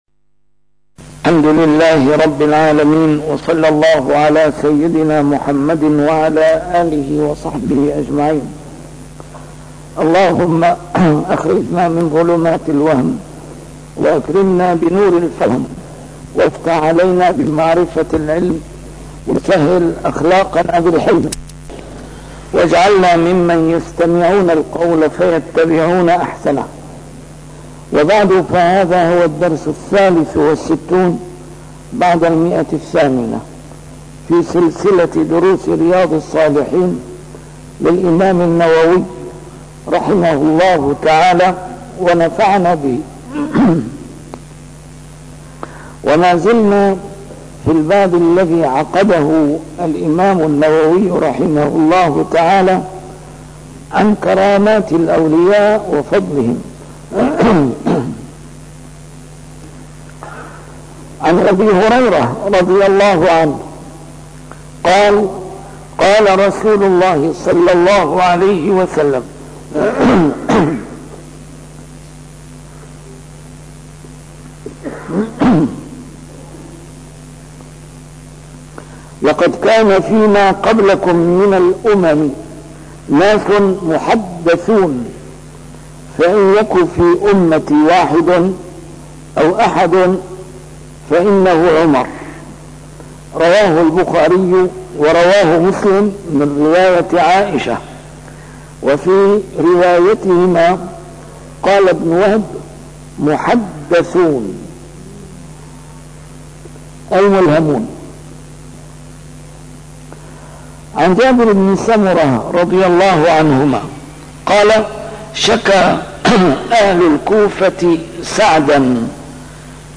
A MARTYR SCHOLAR: IMAM MUHAMMAD SAEED RAMADAN AL-BOUTI - الدروس العلمية - شرح كتاب رياض الصالحين - 863- شرح رياض الصالحين: كرامات الأولياء وفضلهم